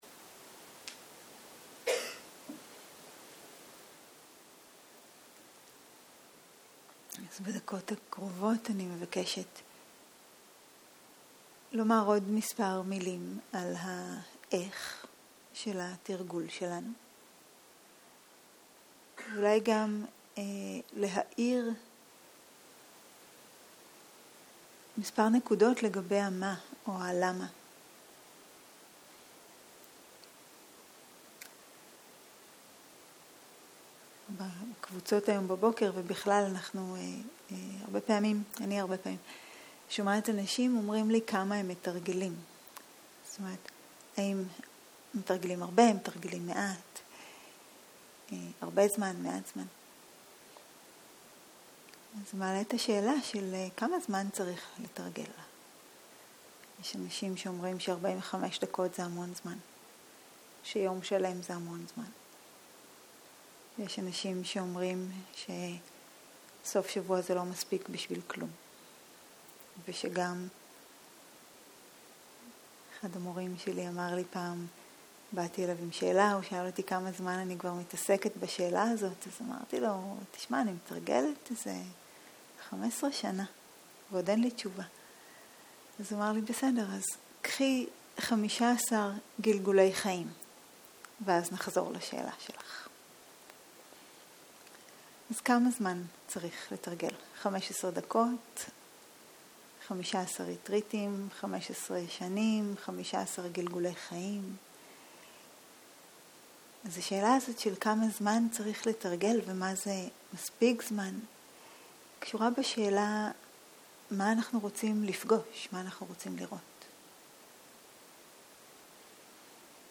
צהריים - מדיטציה מונחית - שלושת מאפייני המציאות - הקלטה 3 0:00 0:00 סוג ההקלטה: Dharma type: Guided meditation שפת ההקלטה: Dharma talk language: Hebrew